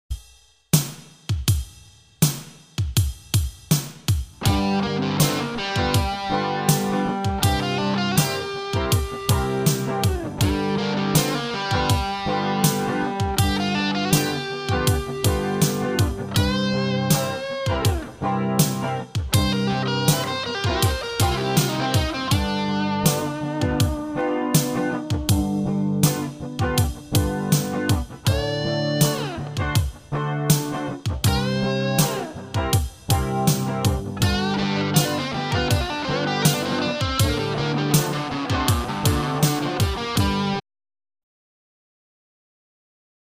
Here's a sample lead using the
G blues type rhythm and lead.
gblues.mp3